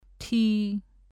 狭口の前舌母音です。
ထီ [tʰì ]宝くじ